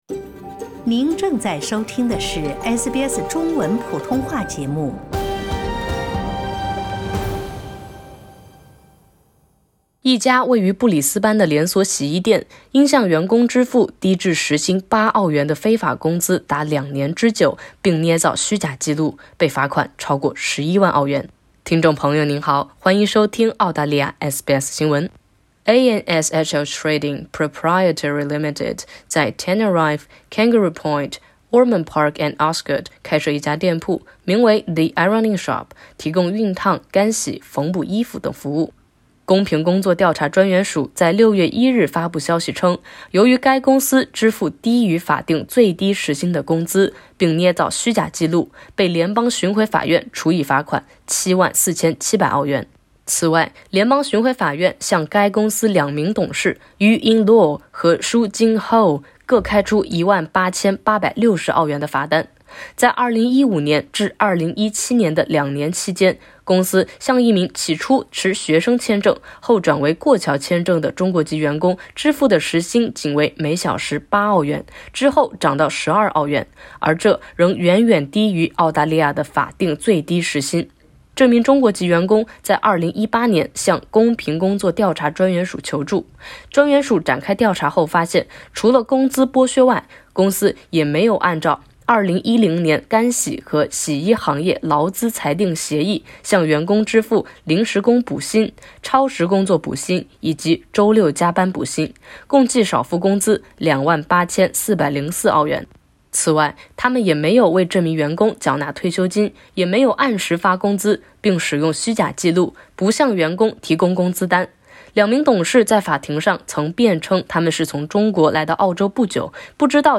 布裡斯班一洗衣店華人老板非法克扣員工工資被罰逾11萬元。（點擊上圖收聽報道）